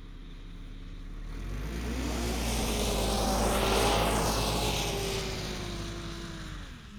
Snowmobile Description Form (PDF)
Subjective Noise Event Audio File - Run 1 (WAV)